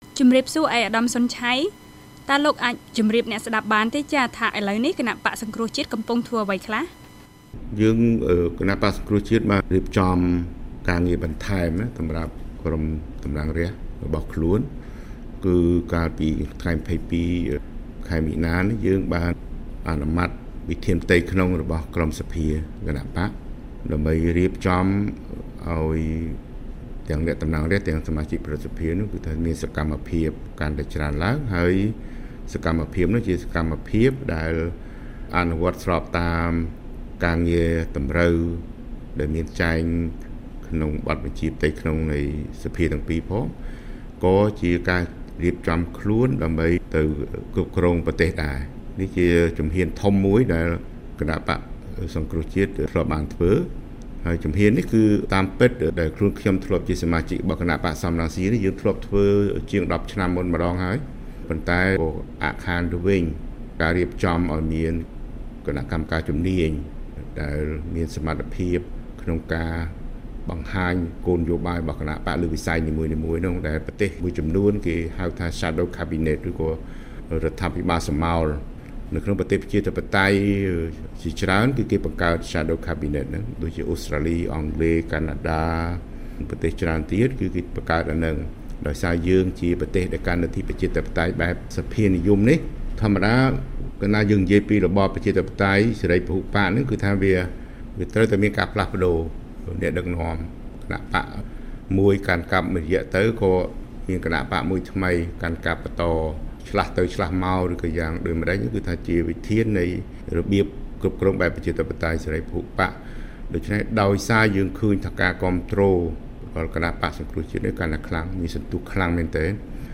បទសម្ភាសន៍ VOA៖ លោកសុន ឆ័យថាគណបក្សសង្គ្រោះជាតិកំពុងពង្រឹងសមត្ថភាពផ្ទៃក្នុង ទោះបីមេដឹកនាំកំពុងរងបញ្ហានយោបាយក៏ដោយ